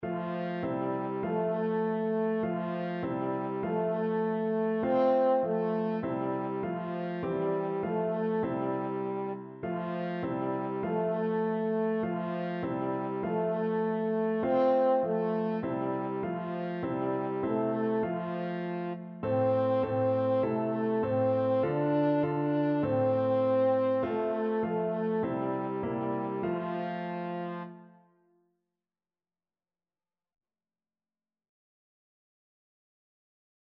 Japanese